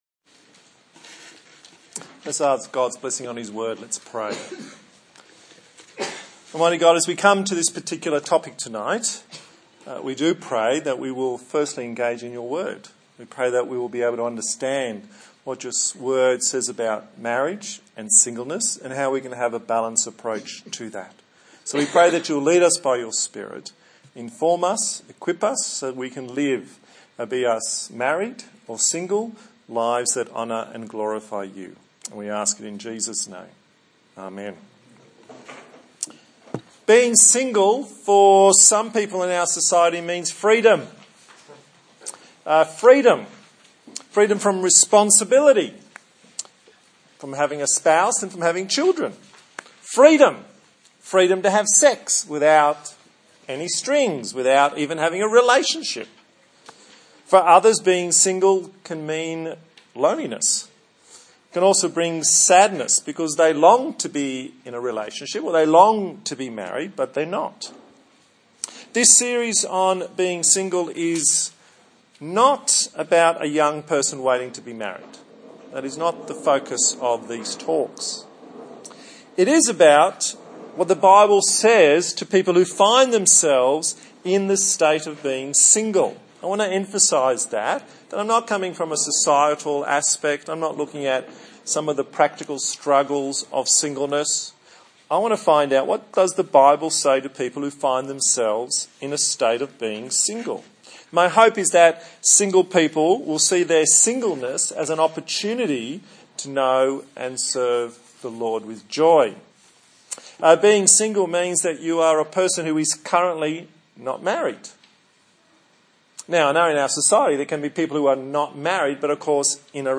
Service Type: TPC@5